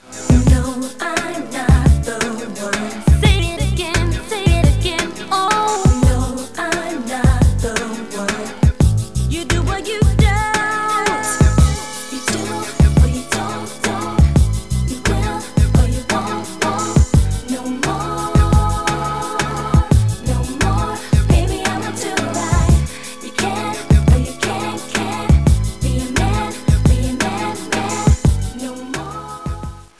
Here are wav-files from famous R&B artists